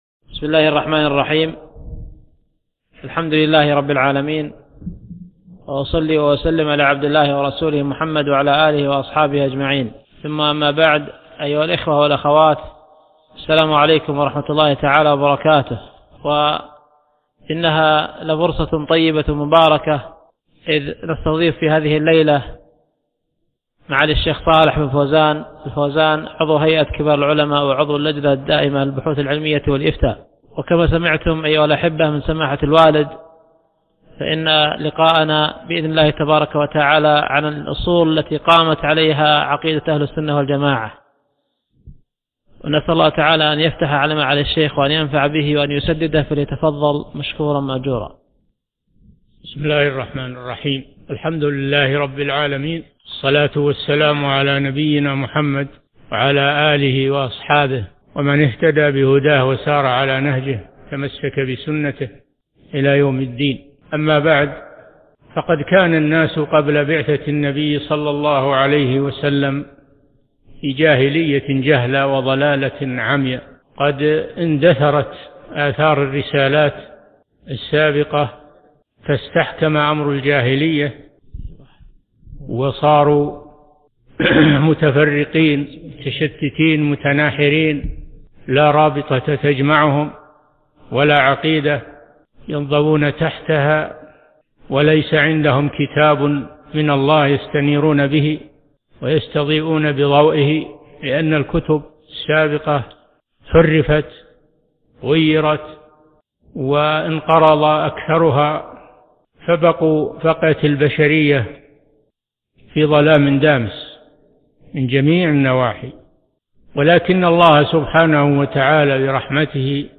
محاضرة - الأصول التي قامت عليها عقيدة أهل السنة و الجماعة